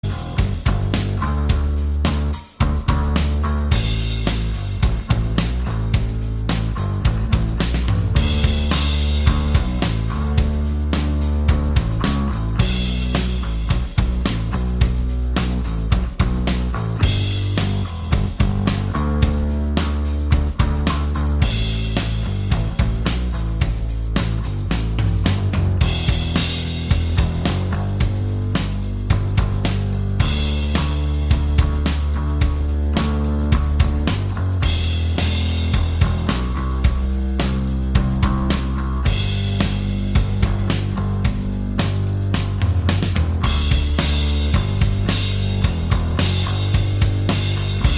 Minus All Guitars Indie / Alternative 5:40 Buy £1.50